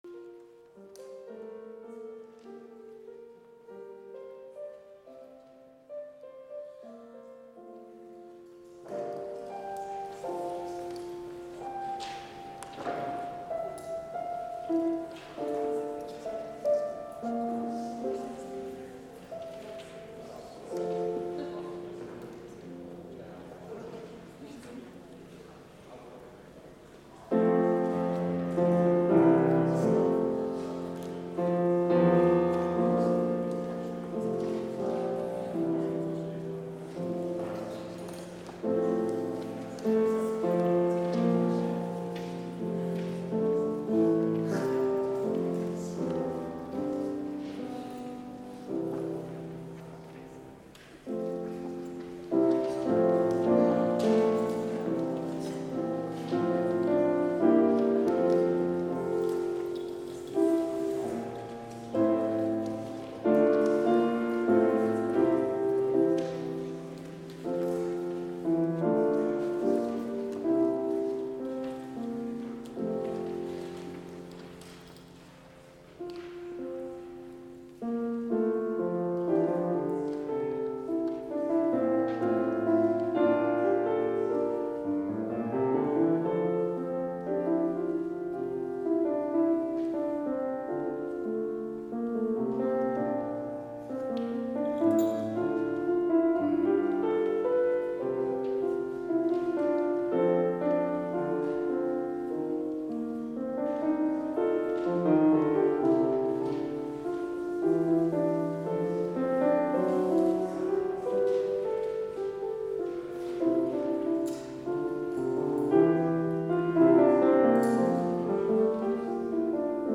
Complete service audio for Chapel - September 29, 2020